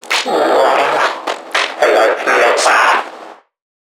NPC_Creatures_Vocalisations_Infected [75].wav